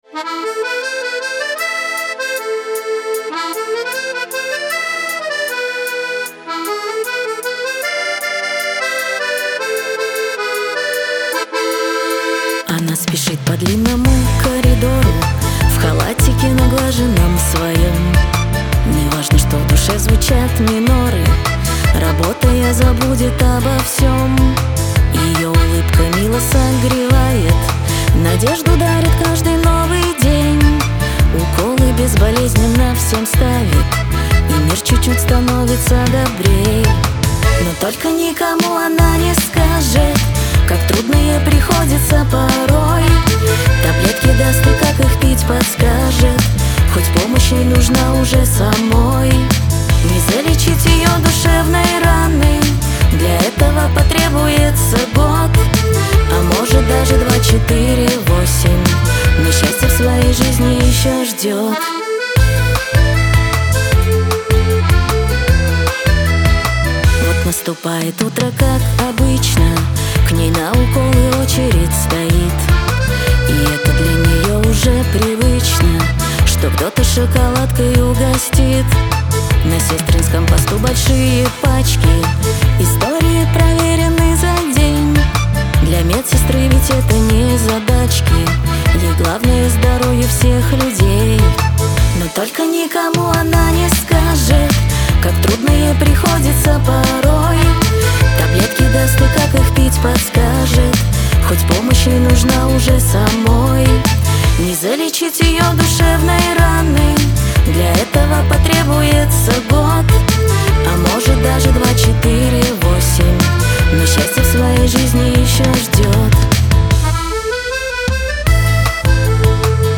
dance , pop